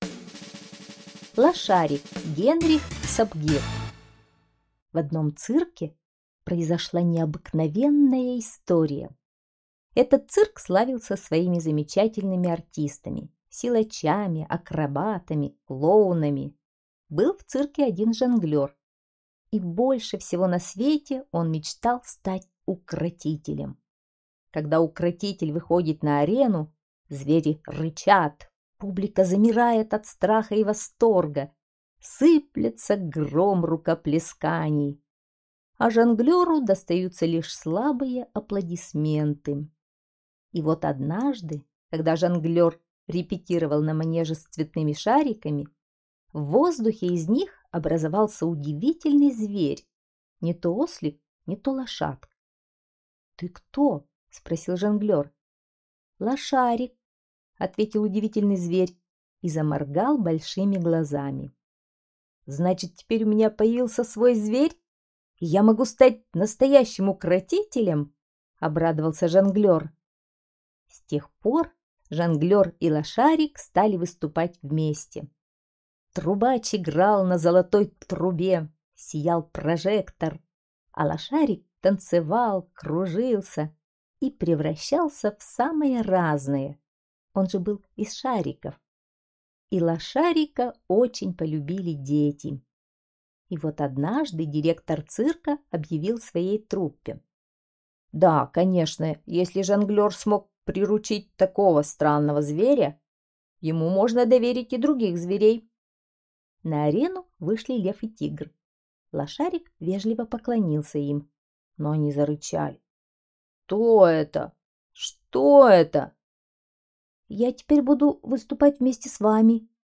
Лошарик - аудиосказка Сапгира Г.В. Сказка про Жонглера, который мечтал стать Укротителем, но у него не было своих зверей.